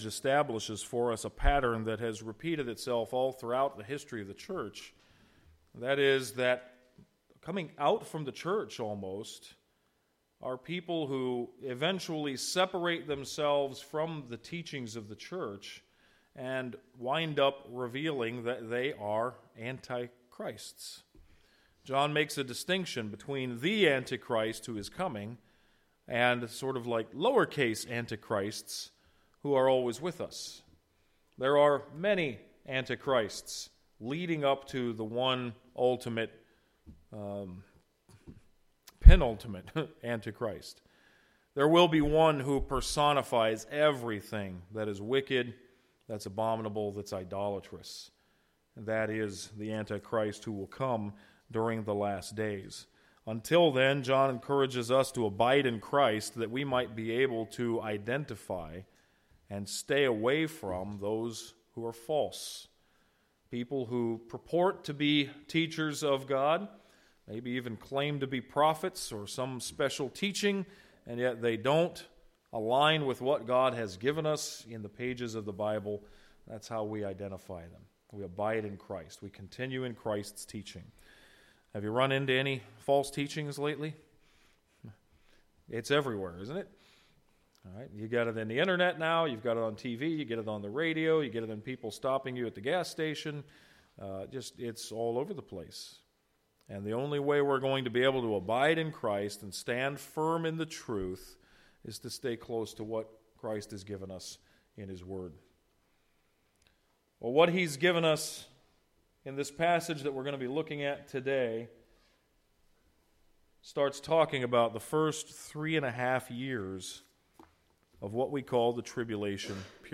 Mendota Bible Church - Audio Sermons